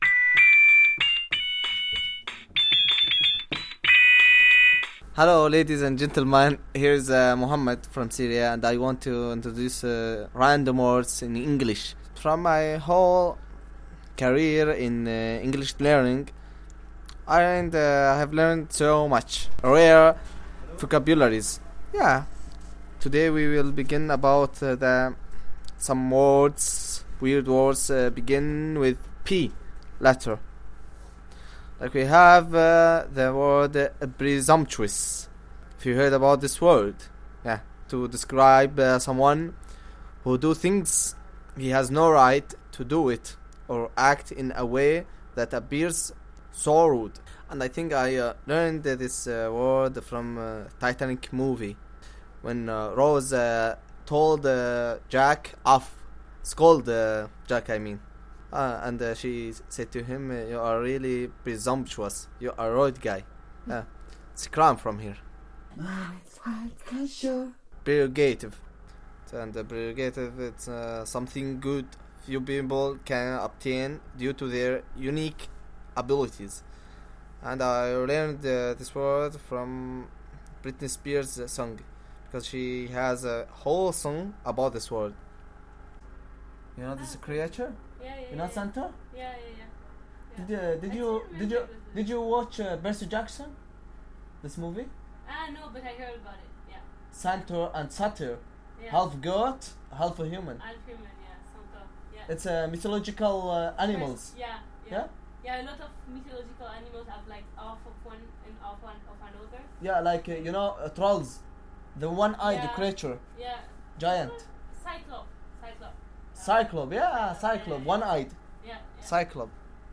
• Radio braodcast